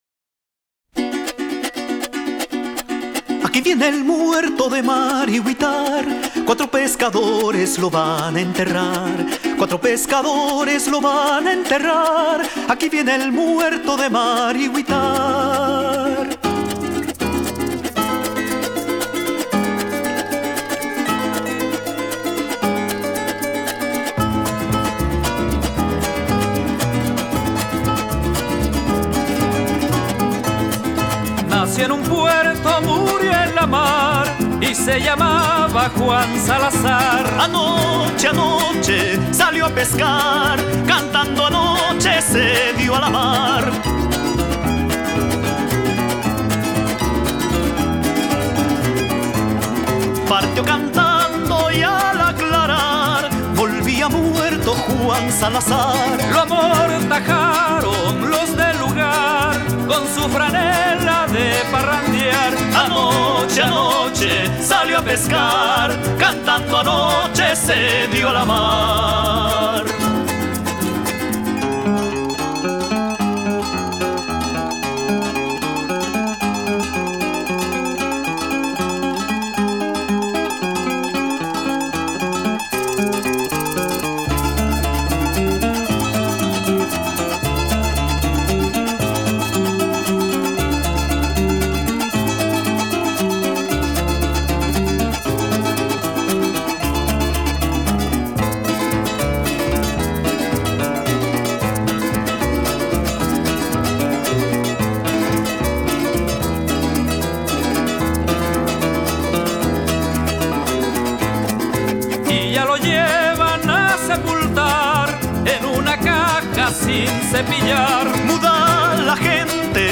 Listen to the best folk song of Latin America ever composed